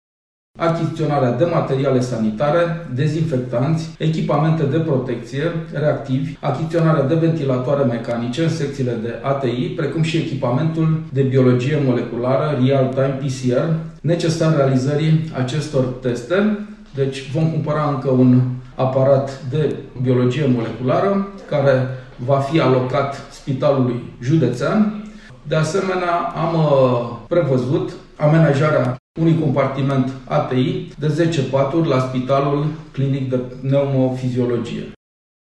Preşedintele Consiliului judeţean Braşov, Adrian Veştea a precizat că bugetul alocat spitalelor a fost suplimentat şi a arătat şi cum vor fi cheltuite sumele alocate.